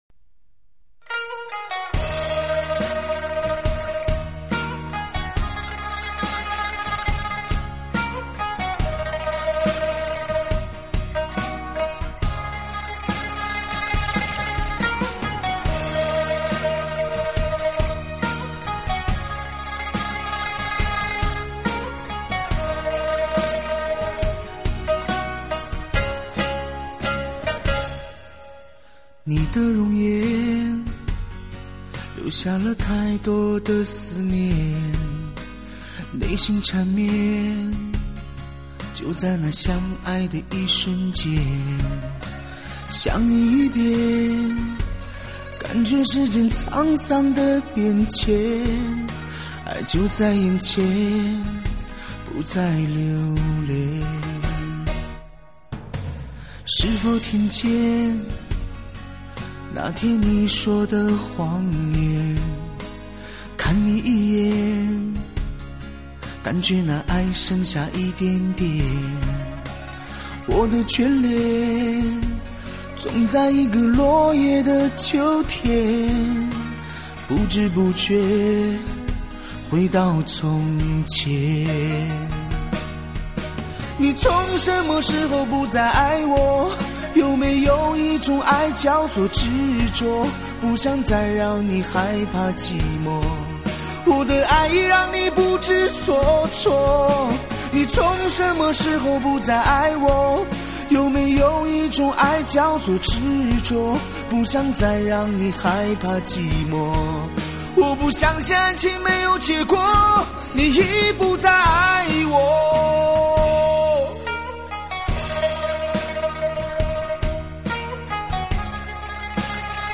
完美、沧桑的悲情男声